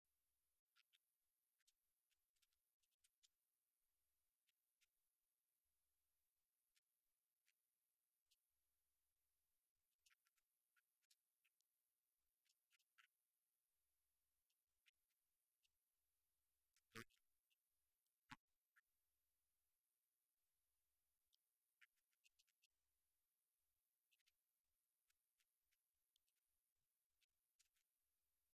Florida Gators head coach Billy Napier spoke highly of the Gator offense.